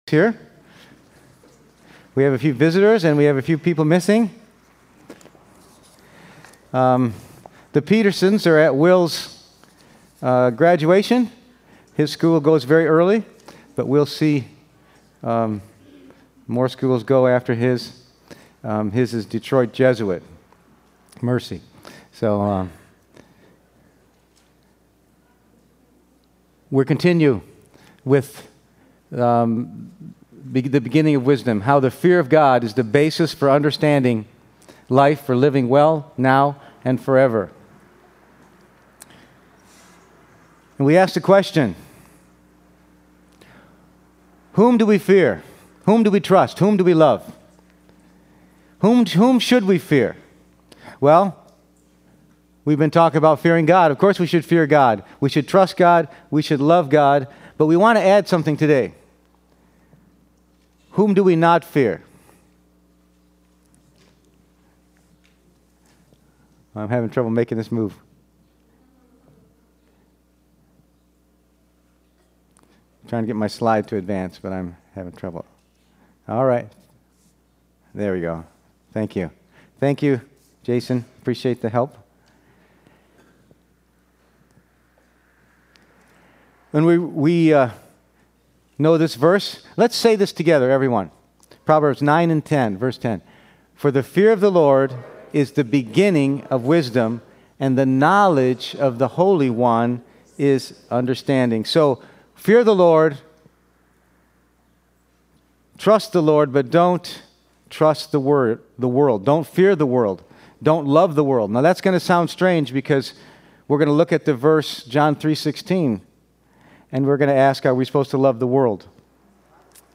Passage: Proverbs 9:10, Proverbs 29:25, James 4:4, 1 John 2:15-17 Service Type: Sunday Morning